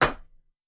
Other Sound Effects
alt-toasterstep1.wav